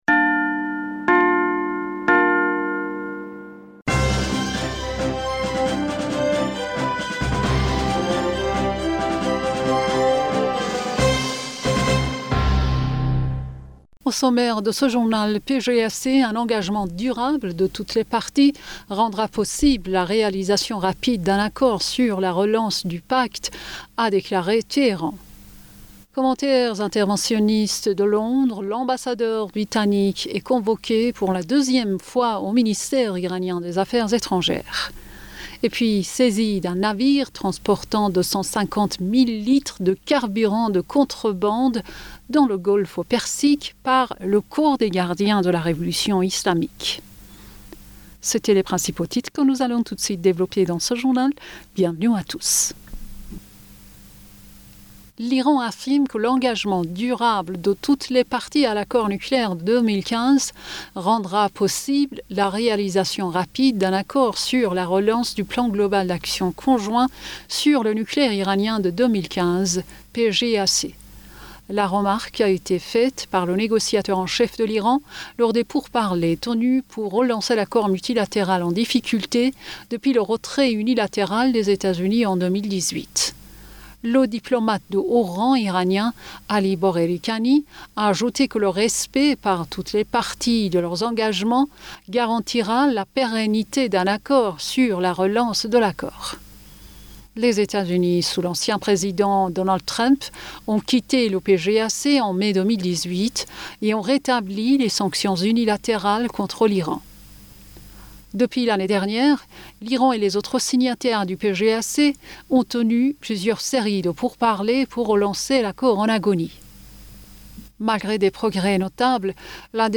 Bulletin d'information Du 05 Octobre